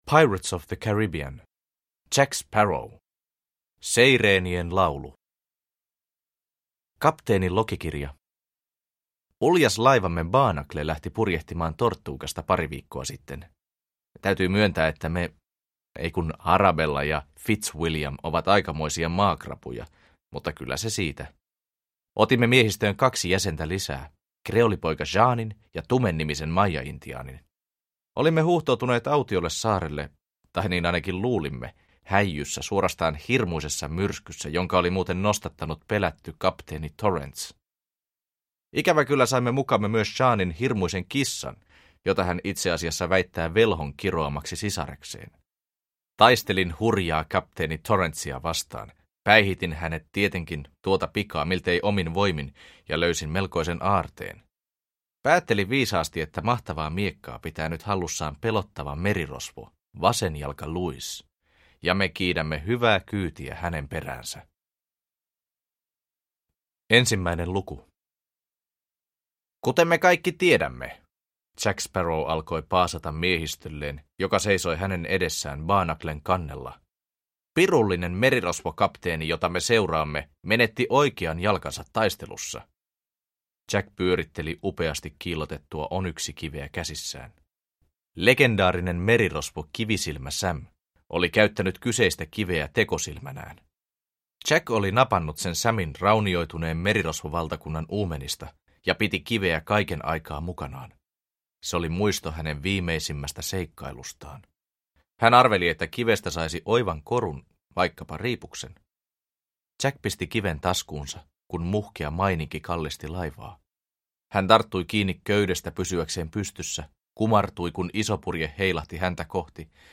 Jack Sparrow 2. Seireenien laulu – Ljudbok – Laddas ner